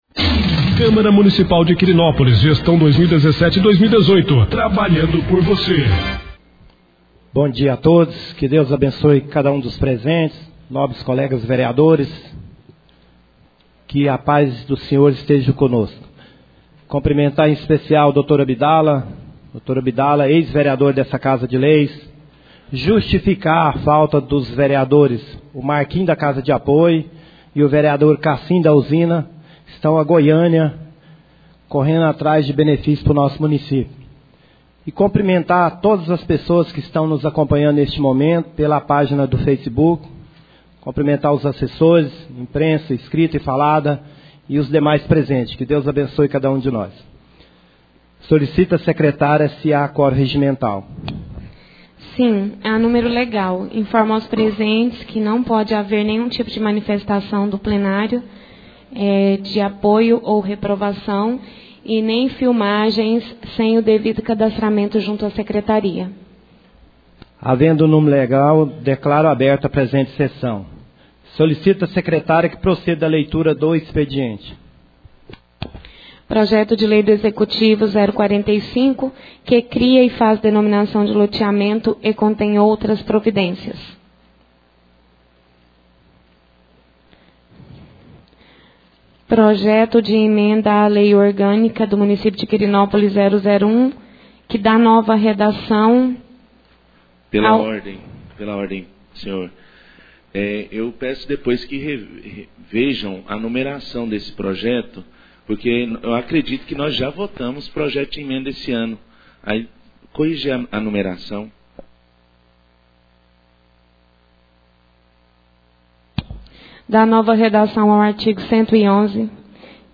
2ª Sessão Ordinária do Mês de Dezembro 2017